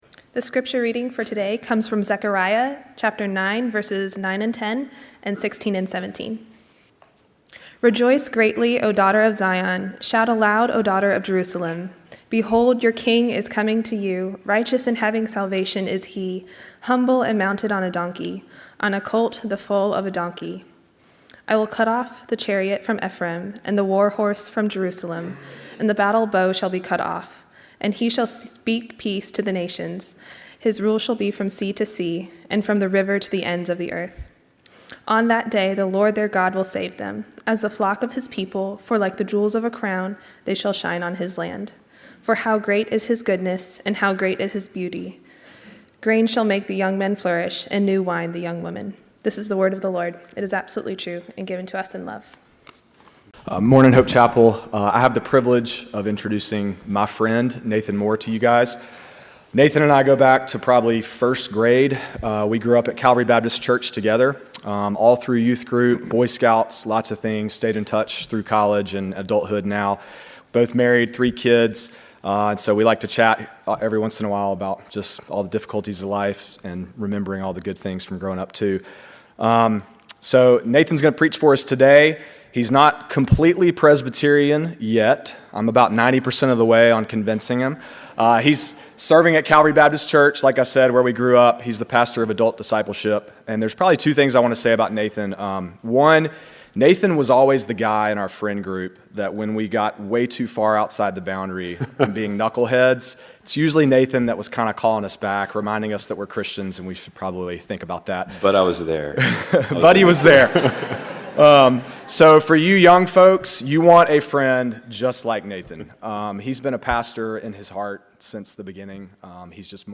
A message from the series "Zechariah."